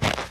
alien-biomes / sound / walking / snow-09.ogg
snow-09.ogg